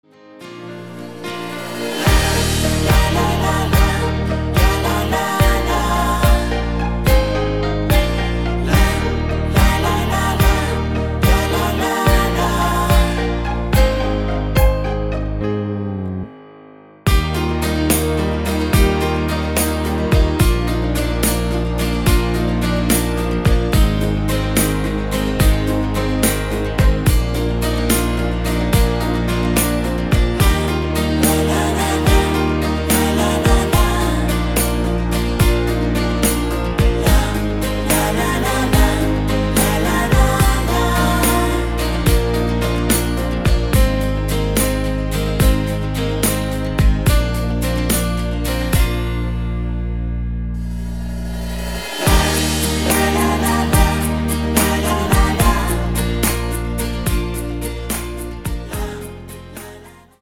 Rhythmus  Slow